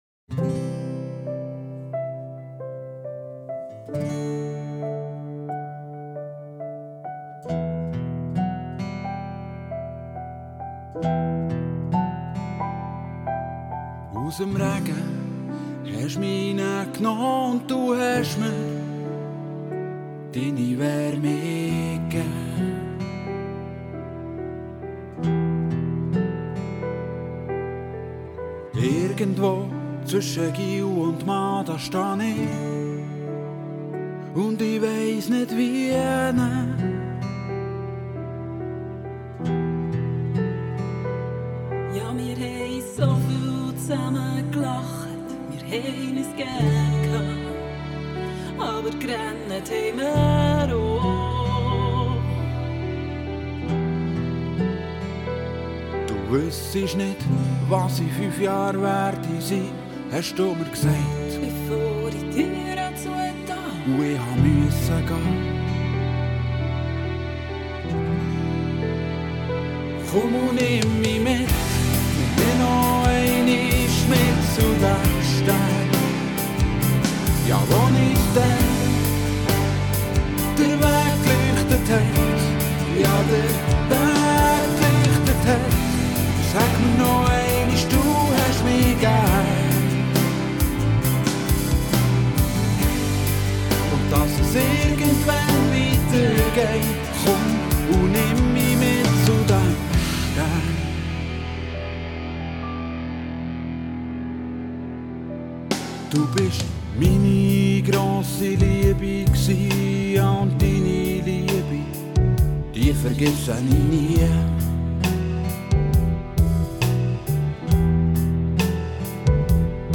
Bärndeutsch Rockband sucht Sängerin Bern
Wir sind eine Bärndeutsch Rockband und spielen vorwiegend eigene Songs von Balladen bis Hardrock.